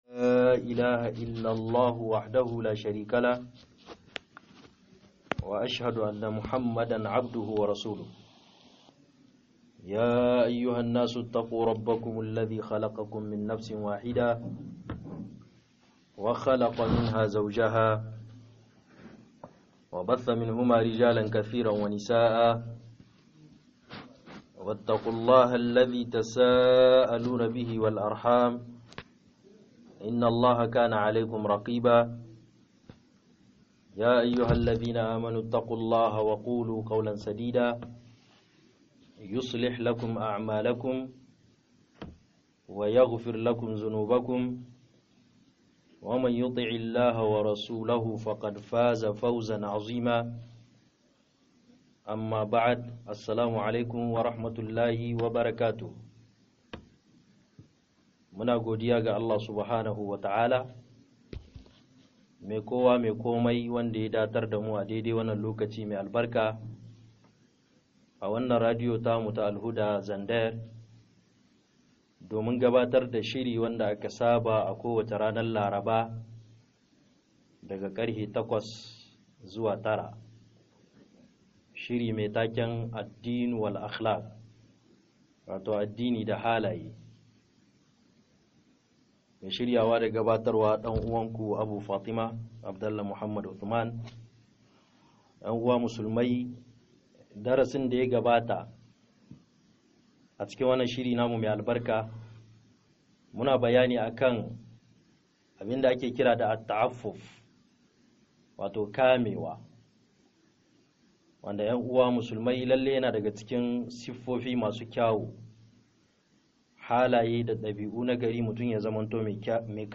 04 ADINI DA HALAYE MASU KEW - MUHADARA